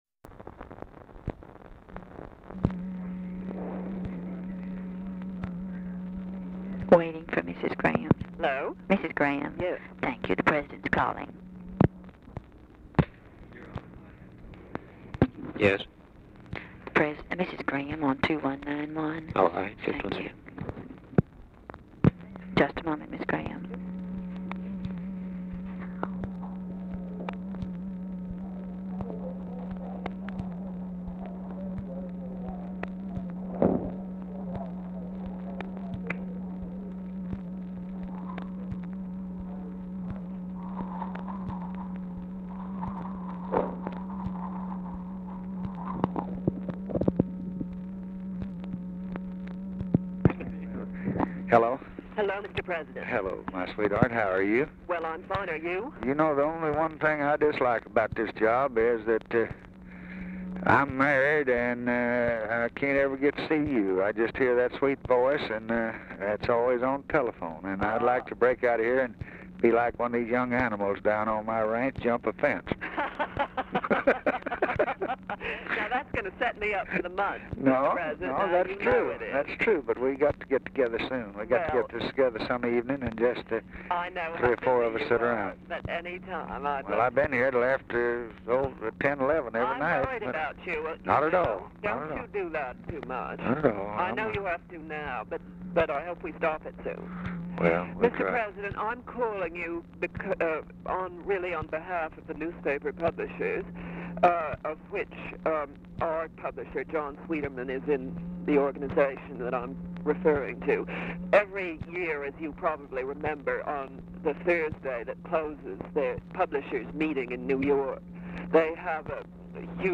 GRAHAM ON HOLD 0:50; OFFICE SECRETARY ANNOUNCES CALL TO BILL MOYERS
Format Dictation belt
Location Of Speaker 1 Oval Office or unknown location
Specific Item Type Telephone conversation